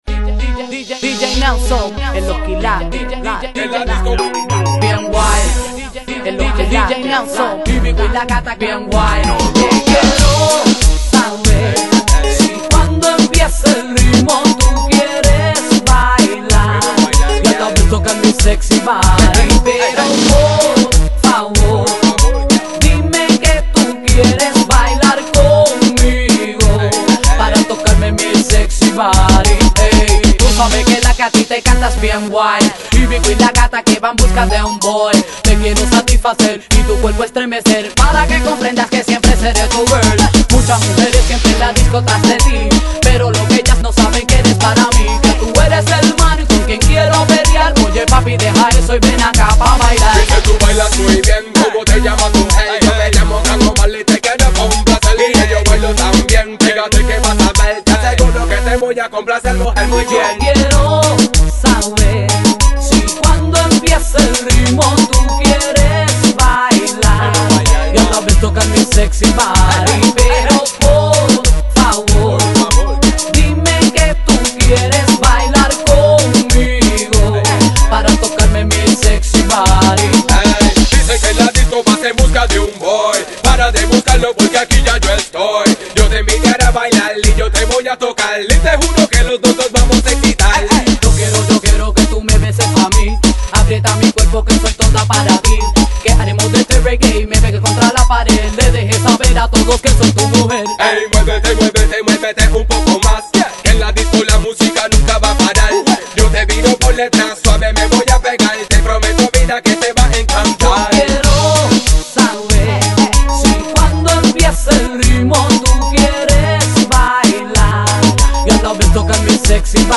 Музыка в стиле Reggaeton